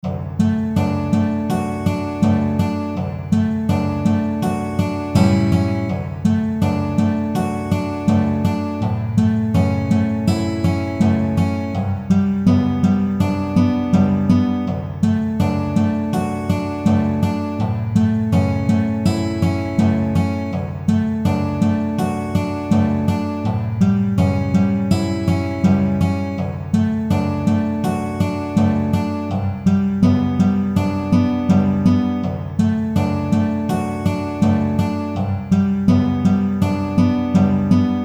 美しい音色が響く優雅な着信音です。